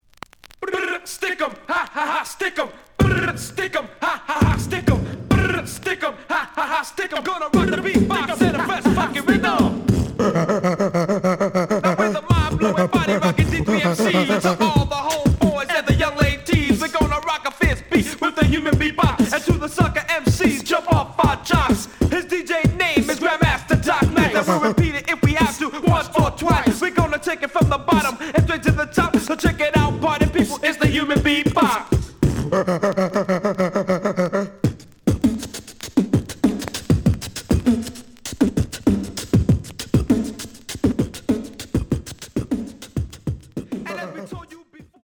The audio sample is recorded from the actual item.
●Genre: Hip Hop / R&B